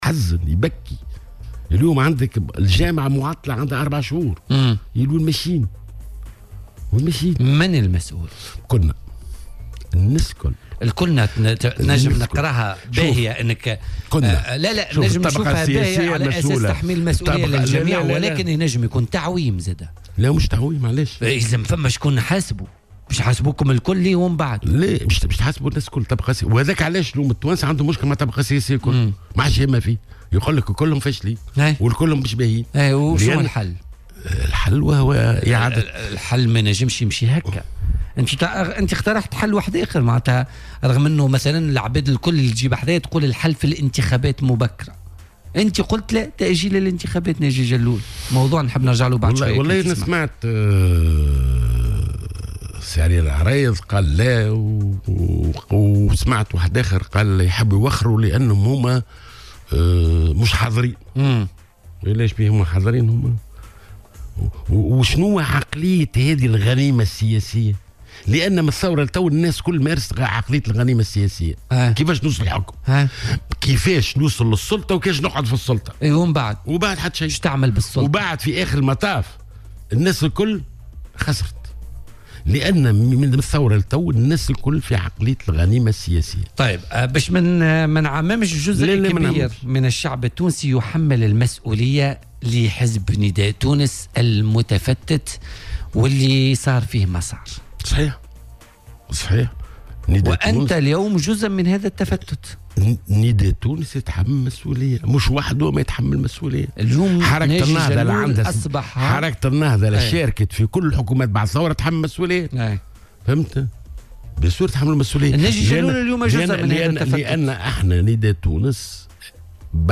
وأضاف ضيف "بوليتيكا" على "الجوهرة أف أم" اليوم الثلاثاء بأنه يتمتّع بالشرعية السياسية، معربا عن استعداده للانسحاب من الأمانة العامة بنداء تونس مقابل ايجاد حلول جذرية لانهاء صراعات الشقوق داخل الحزب.